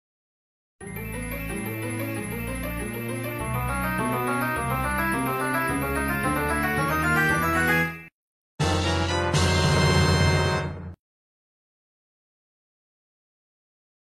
Sons - Effets Sonores